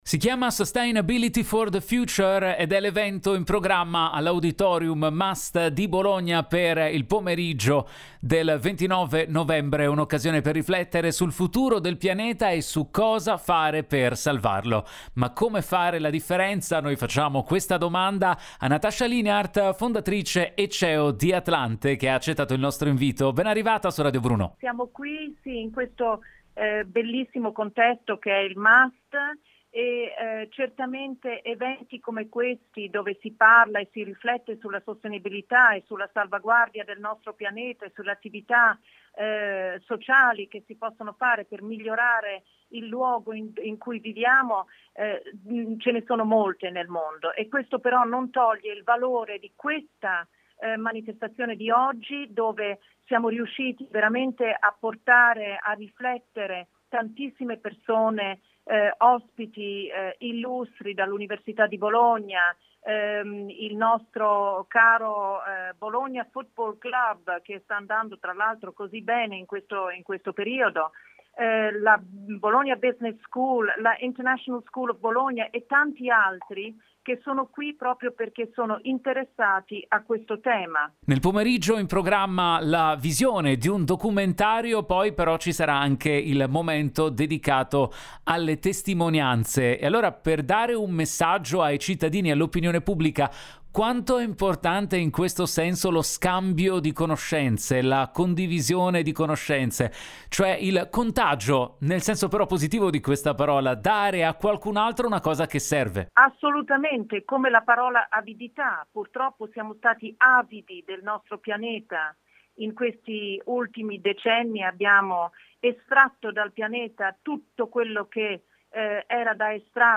Home Magazine Interviste “Sustainability for the future” va in scena a Bologna